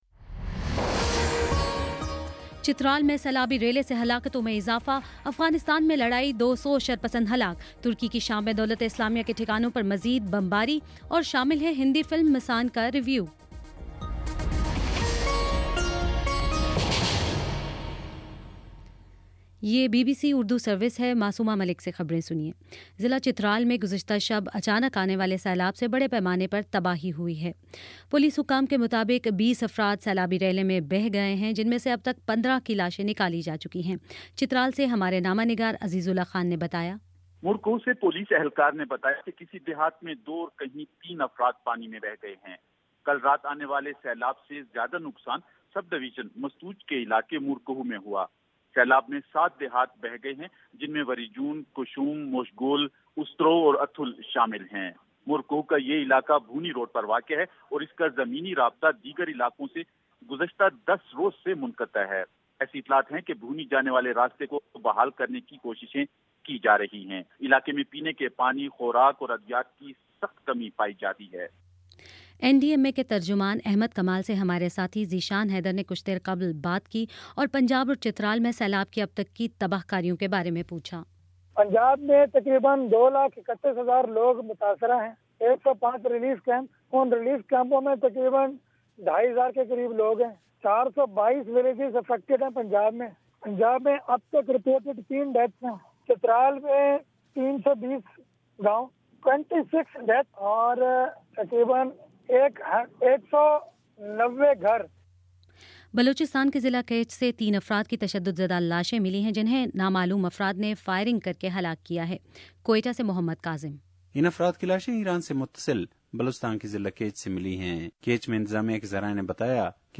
جولائی 25: شام پانچ بجے کا نیوز بُلیٹن